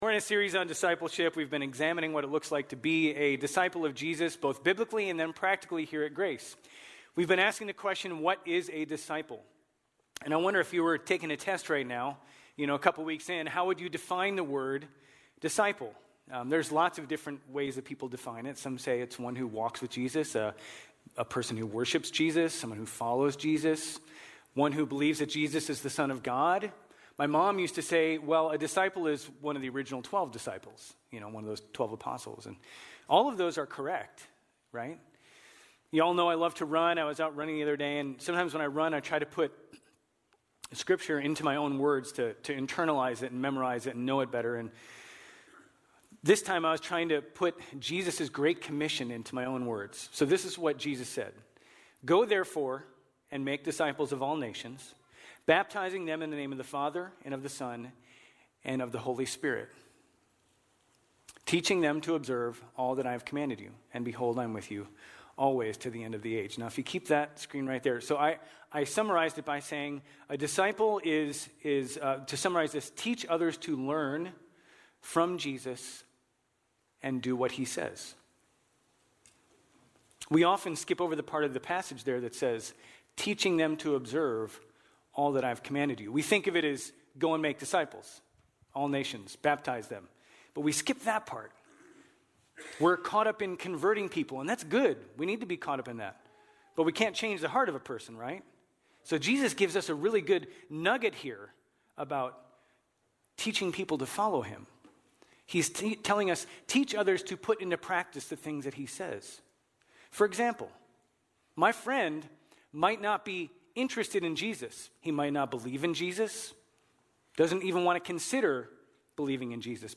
GCC-OJ-February-13-Sermon.mp3